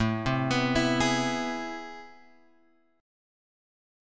AmM9 chord {5 3 x 4 5 4} chord